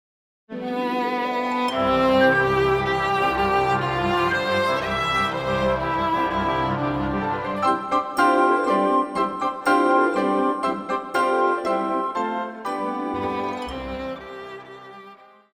Pop
Violin
Band
Instrumental
World Music
Only backing